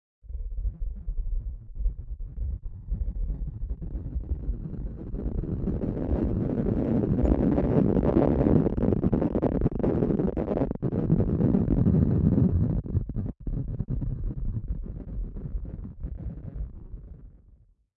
bajo agua LOOP
描述：under water like sound looped, it's a beach fieldrecording with a low pass, so processed.
标签： drone fieldrecording long low underwater water
声道立体声